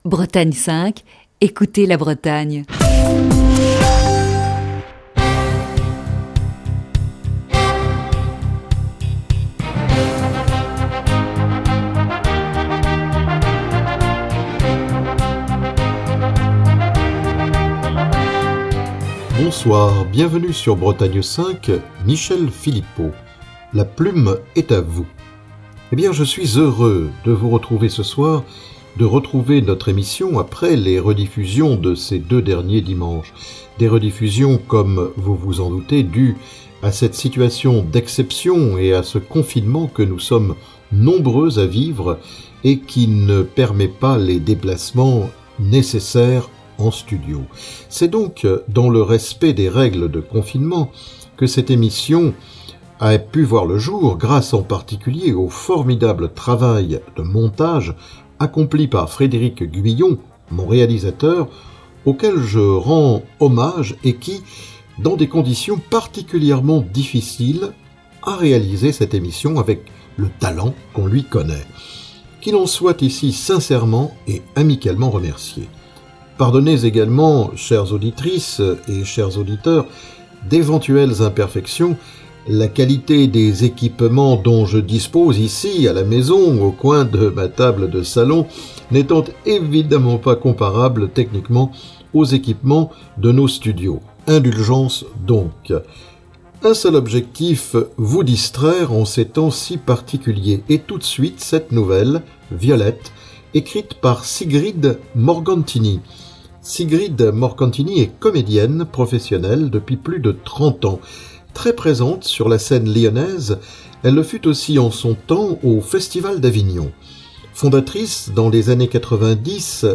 Bretagne 5 vit à l'heure du confinement.